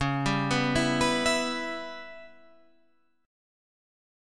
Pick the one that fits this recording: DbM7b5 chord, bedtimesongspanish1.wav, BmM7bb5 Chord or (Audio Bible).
DbM7b5 chord